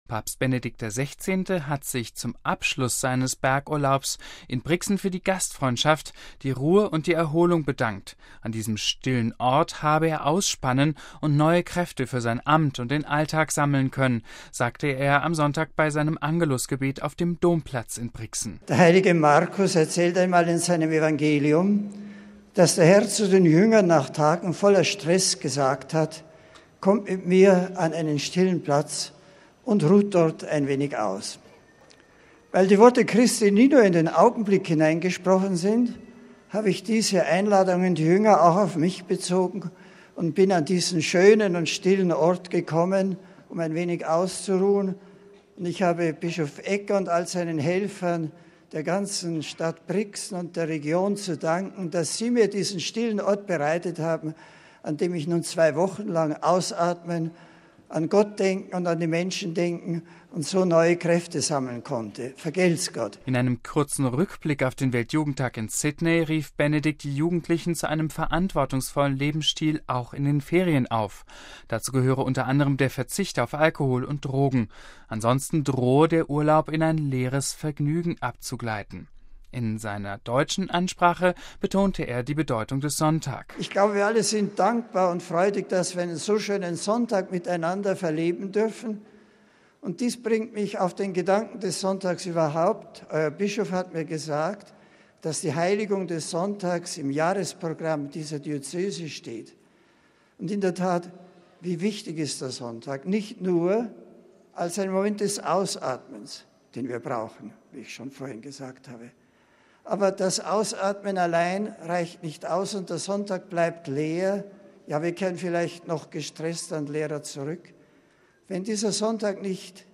An diesem stillen Ort habe er ausspannen und neue Kräfte für sein Amt und den Alltag sammeln können, sagte er am Sonntag bei seinem Angelus-Gebet auf dem Domplatz in Brixen.